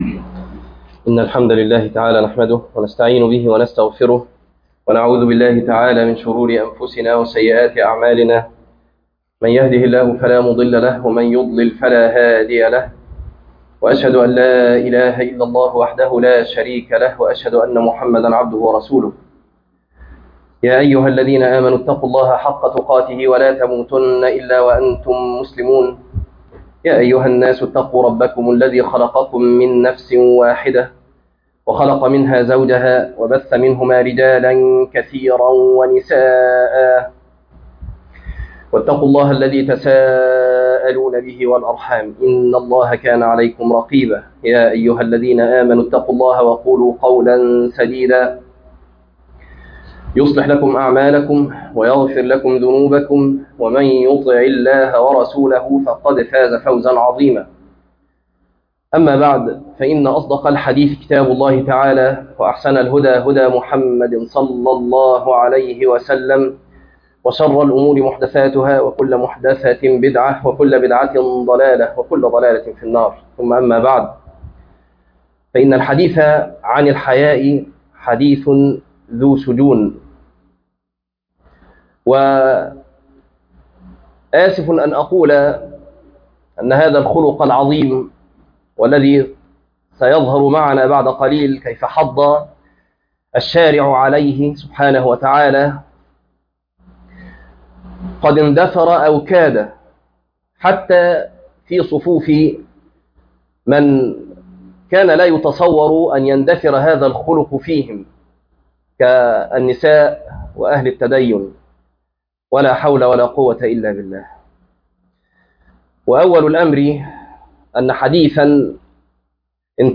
الحياء خير كله - خطبة